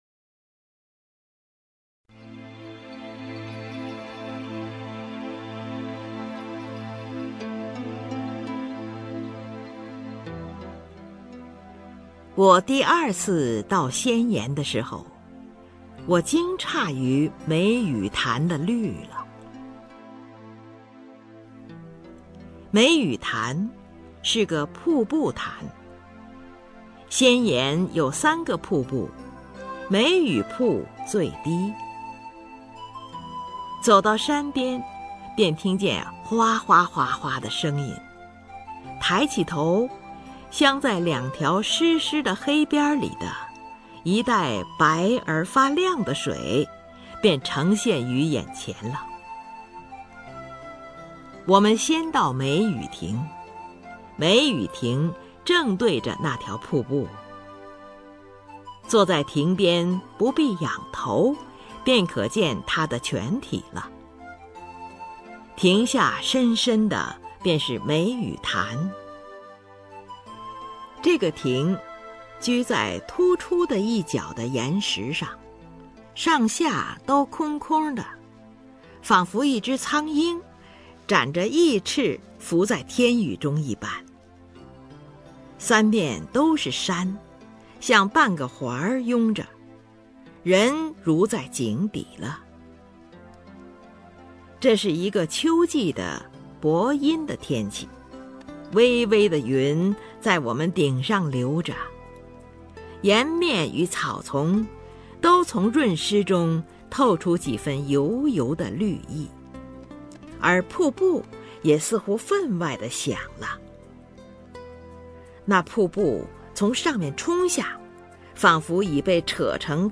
首页 视听 名家朗诵欣赏 林如
林如朗诵：《绿》(朱自清)